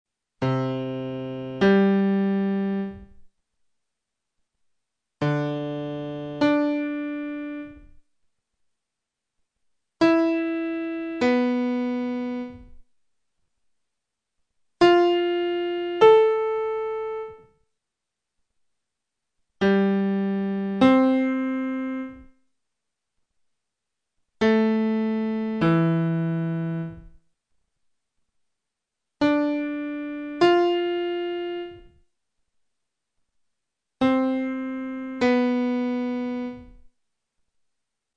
Ascolto selettivo
Ascolta queste 8 coppie di suoni.